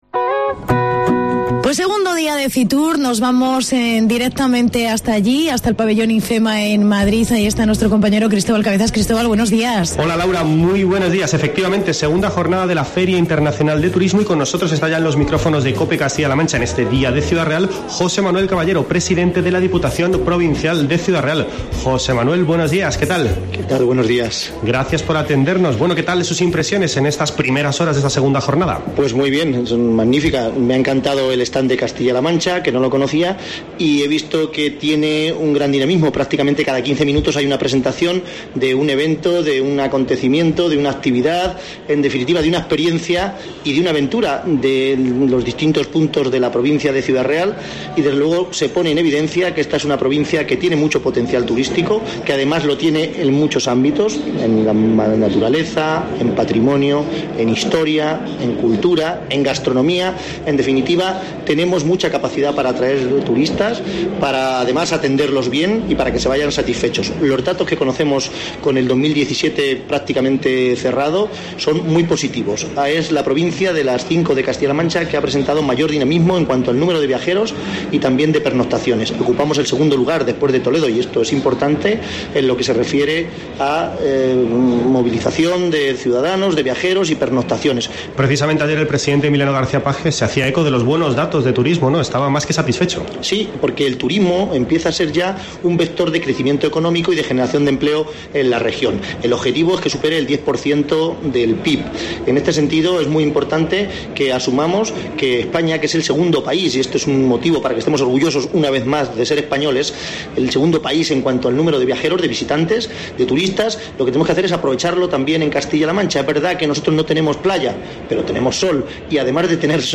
Entrevista al pte Diputación C.Real. José M. Caballero